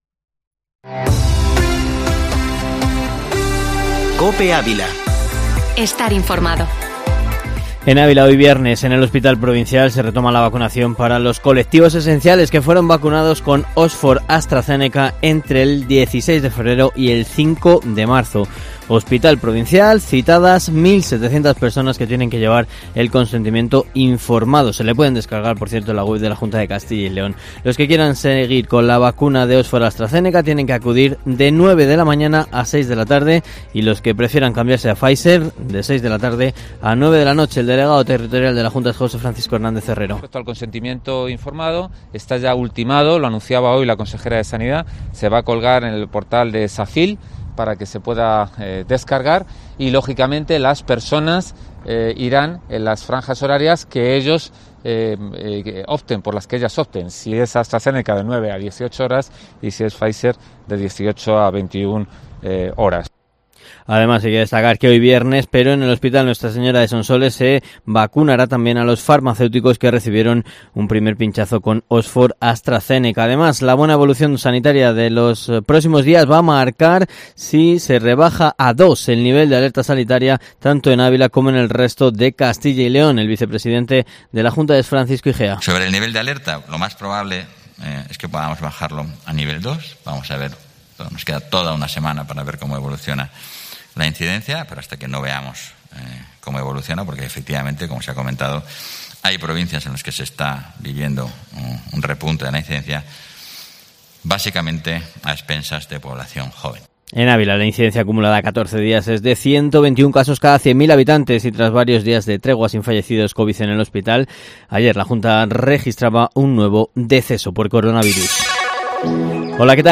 Informativo Matinal Herrera en COPE Ávila 28/05/2021